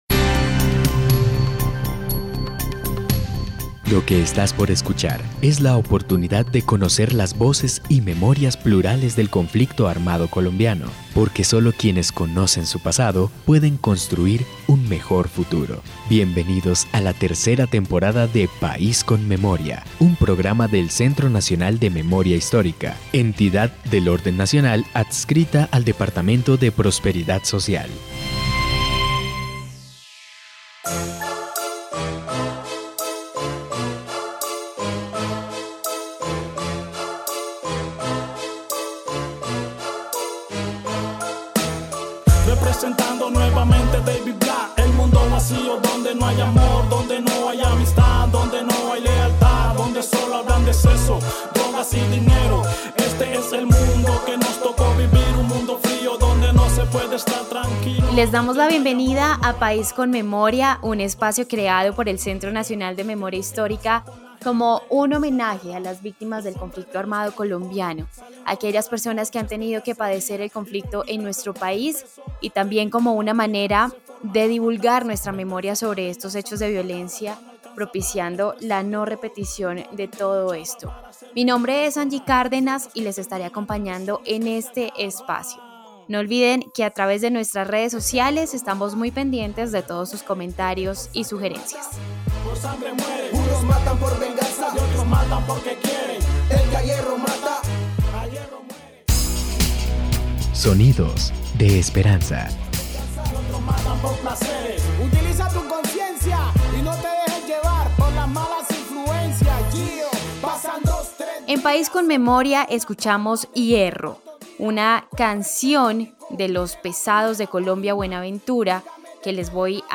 Descripción (dcterms:description) Capítulo número 31 de la tercera temporada de la serie radial "País con Memoria".